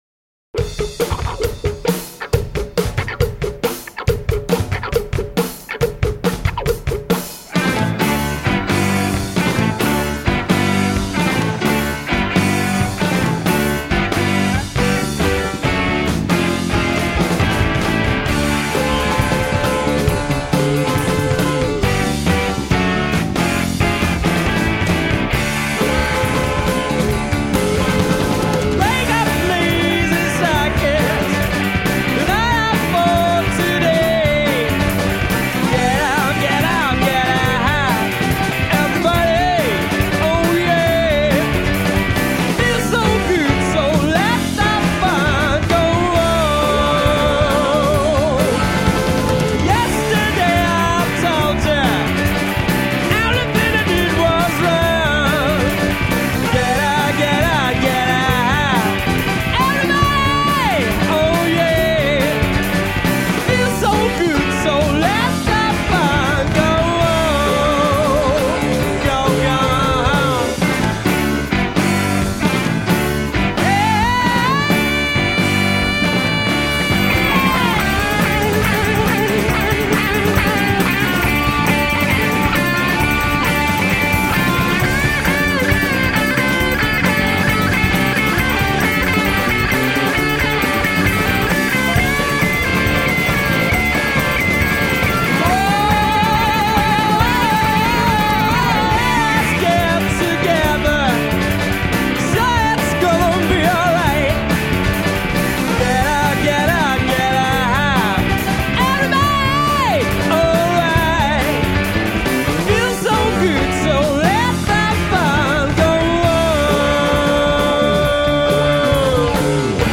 Tagged as: Alt Rock, Rock, Classic rock, Prog Rock